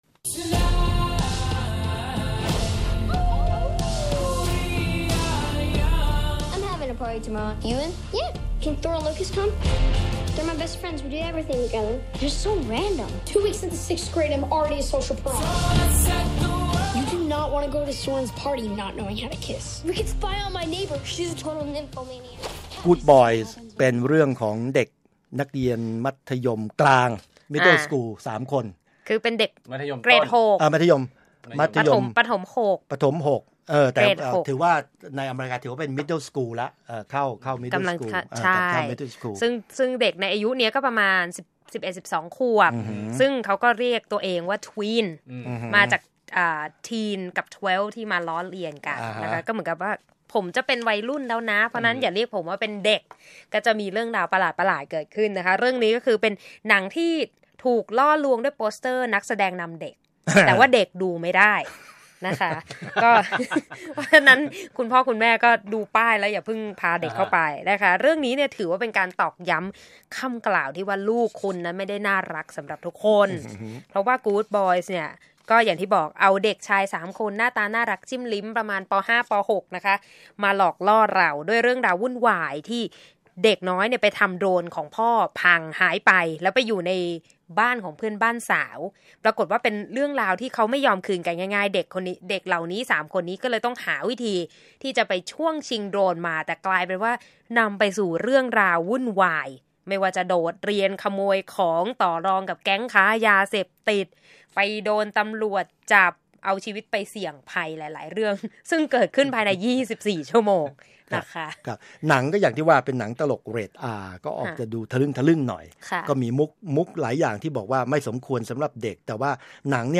วิจารณ์ภาพยนตร์